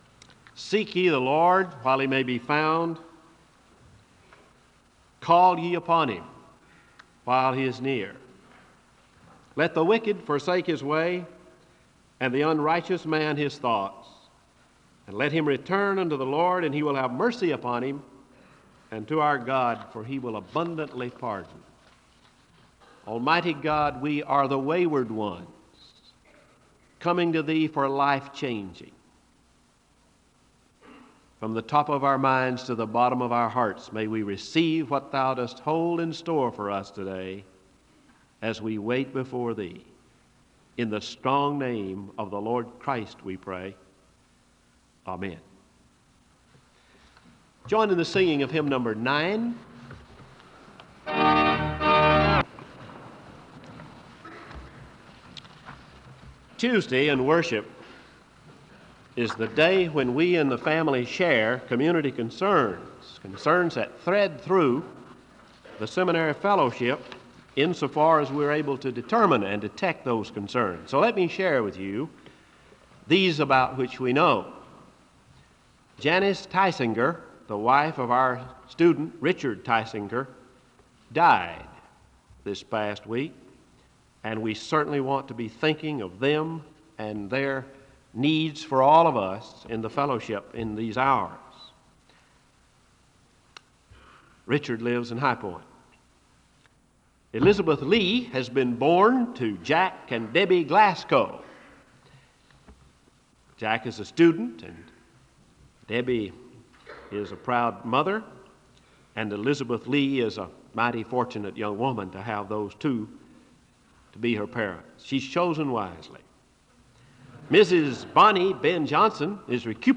SEBTS Adams Lecture
MS. The service begins with a word of prayer (0:00:00-0:00:52).